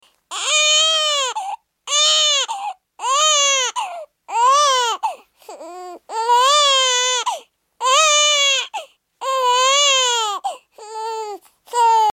Baby Crying High Pitch
Sound Effects